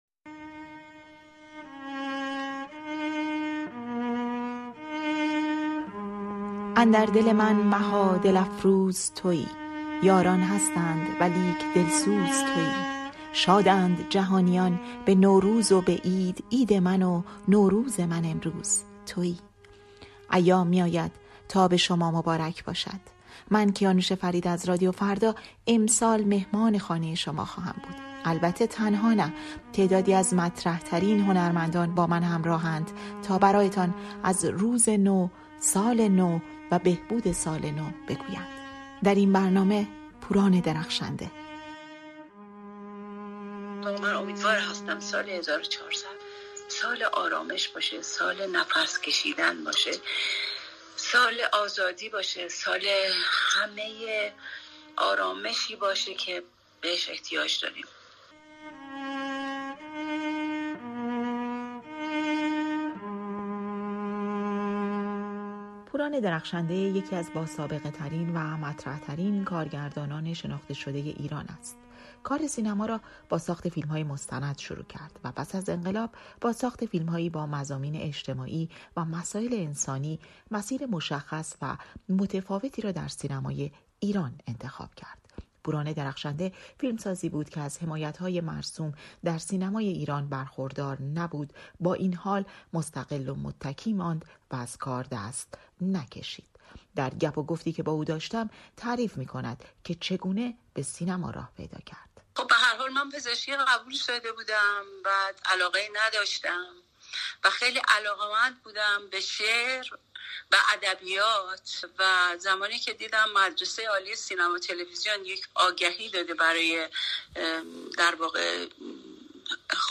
گفت‌وگوی نوروزی با پوران درخشنده؛ «سالی برای نفس کشیدن»